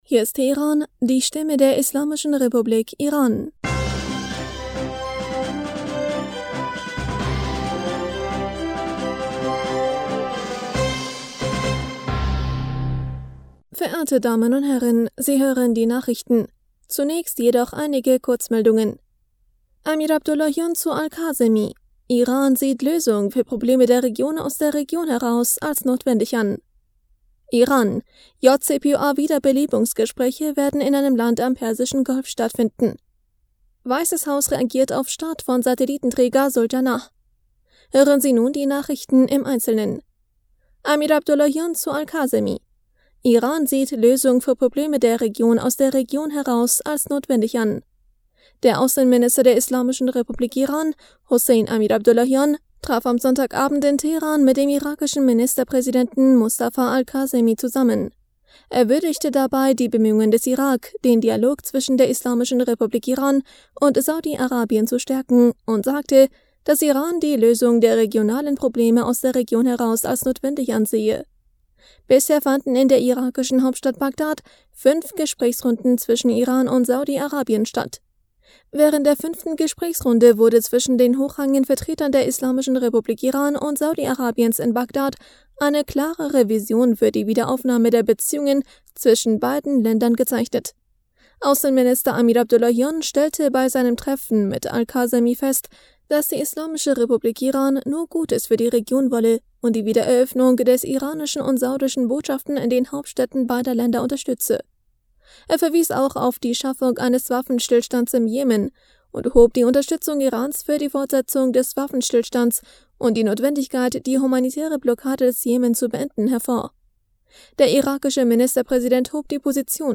Nachrichten vom 27. Juni 2022
Die Nachrichten von Montag dem 27. Juni 2022